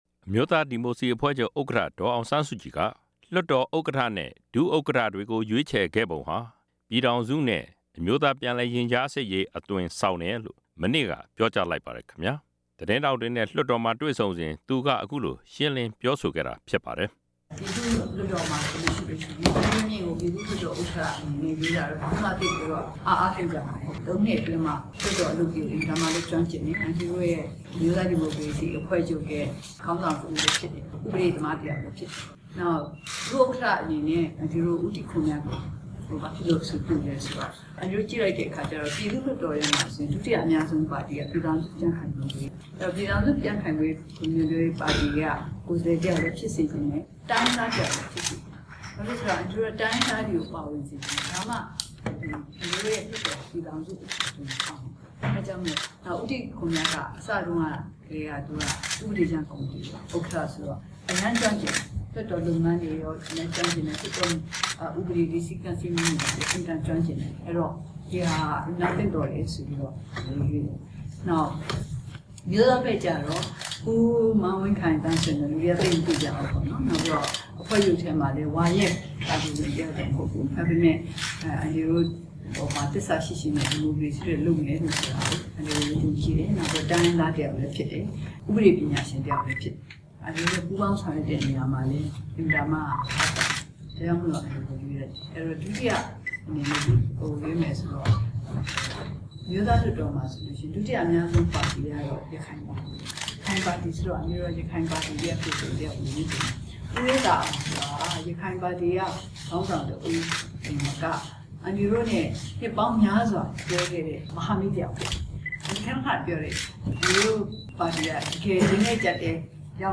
ဒေါ်အောင်ဆန်းစုကြည်က သတင်းထောက်တွေကို လွှတ်တော်မှာ မနေ့ညနေက တွေ့ဆုံပြီး လွှတ်တော် ဥက္ကဌနဲ့ ဒုတိယ ဥက္ကဌ ရာထူးတွေရွေးချယ်ခဲ့ပုံကို ရှင်းလင်းပြောကြားခဲ့ပါတယ်။